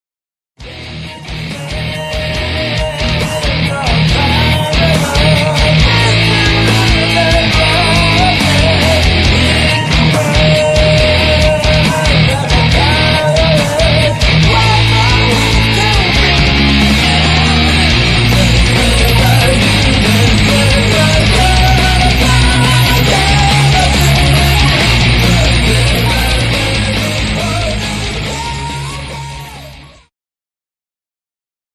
ジャンル HeavyMetal